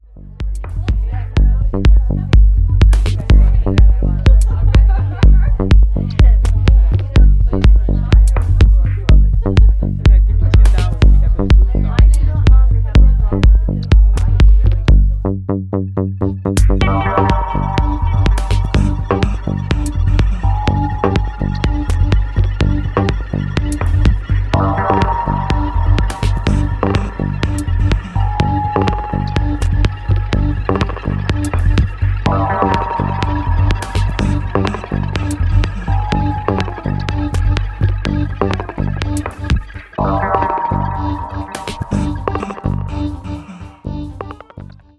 supplier of essential dance music